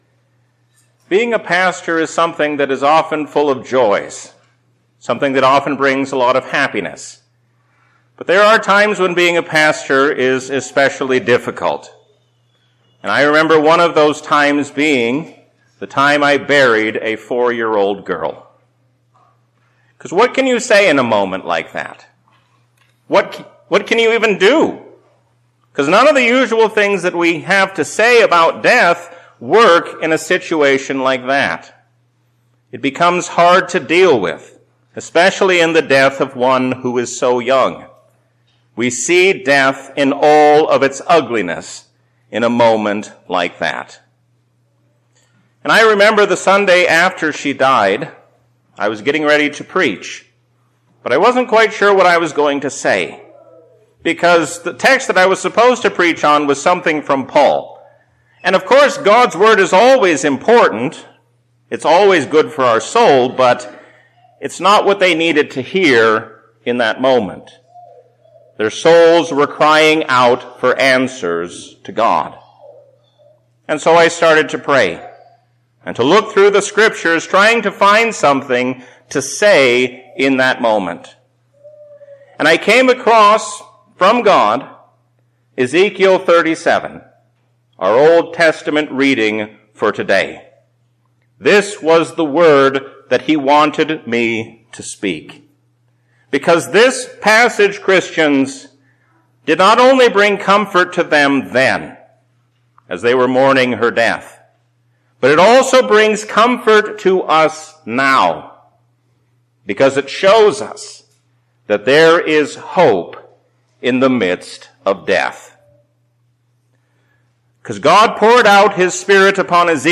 A sermon from the season "Easter 2024." Do not give in to the allure of this world, but stand fast, knowing that God will soon bring all evil to an end.